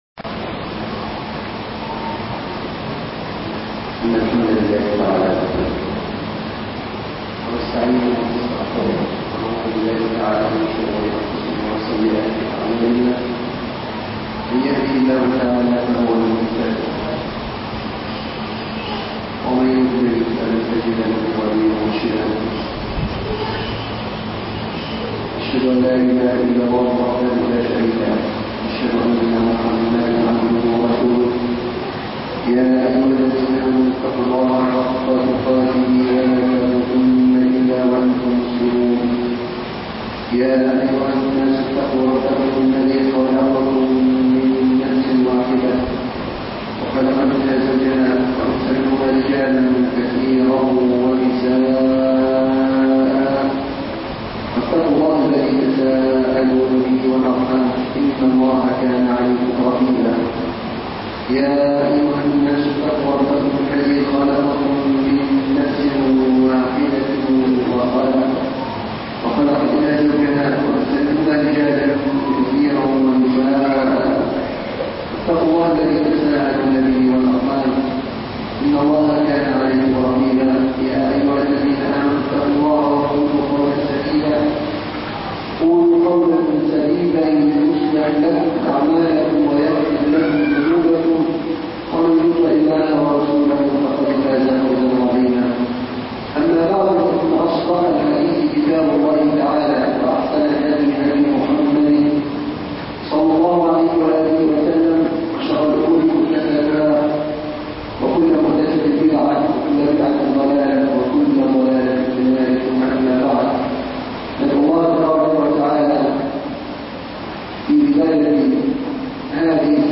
الدرس الثاني (غزوة بدر